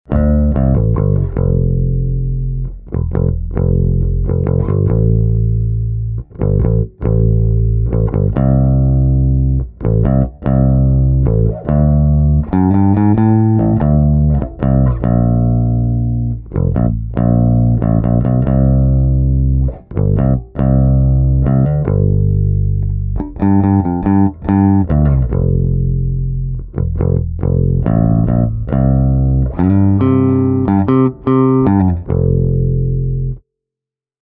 Höfner 500/1:n soundi on iso, lämmin ja pyöreää, mutta bassolta saa myös mukavasti murahdusta mukaan, etenkin roundwound-kielillä.
Vaikka soitan pääsääntöisesti sormityylillä, käytän Höfnerillä aina plektran, koska kielet istuvat melko korkealla kannen yllä ja myös hyvin lähellä toisiaan.
…ja tämän lisäksi vielä lyhyt ote yhden biisin bassoraidasta:
Höfner 500/1 sound
hc3b6fner-bass-sound.mp3